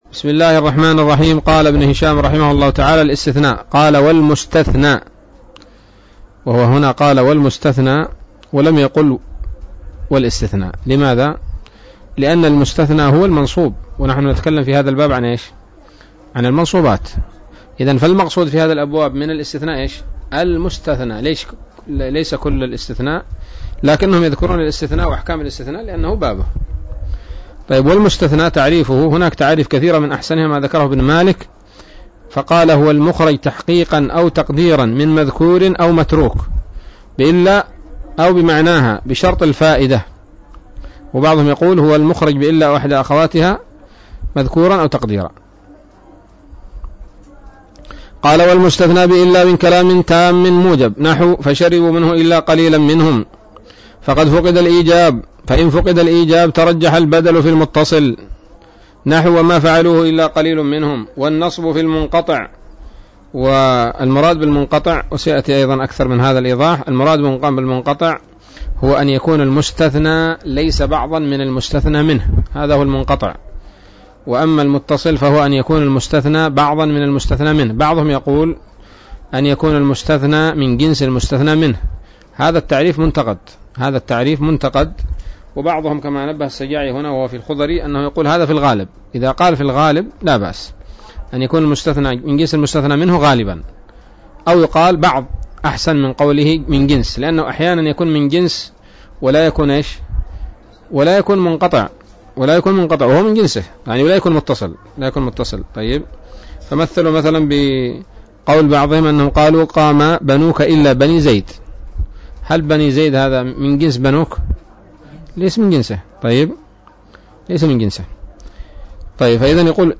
الدرس المائة من شرح قطر الندى وبل الصدى